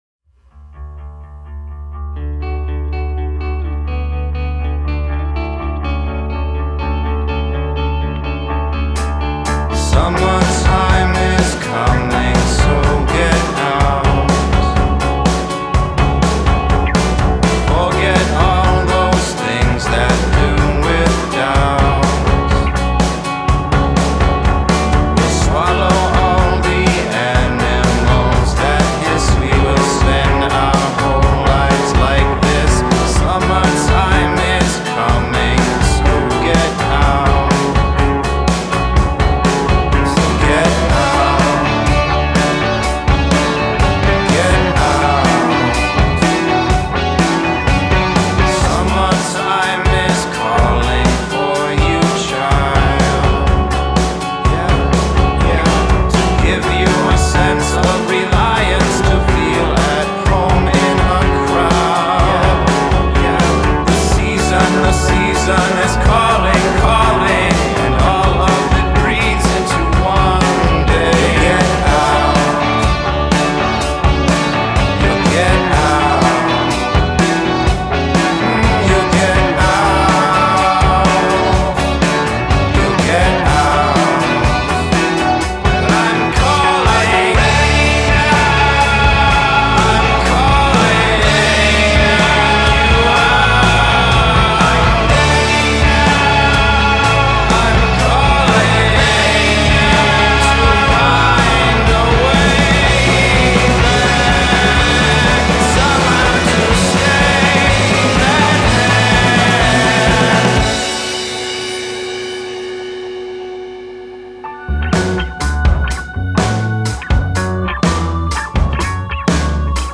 the man with the compelling lead voice